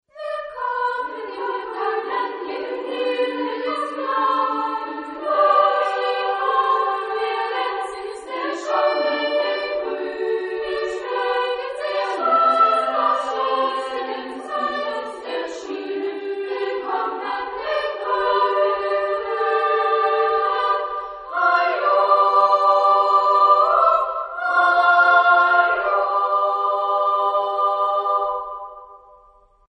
Época : Siglo 19
Género/Estilo/Forma: Folklore ; Profano
Tipo de formación coral: SSA  (3 voces Coro femenino )
Tonalidad : sol mayor